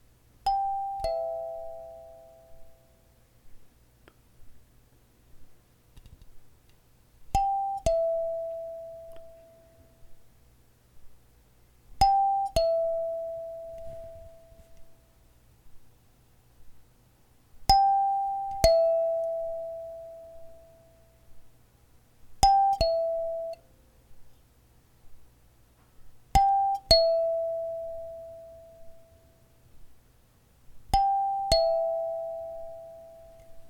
several shots of doorbell
bell chime ding dong door doorbell home house sound effect free sound royalty free Sound Effects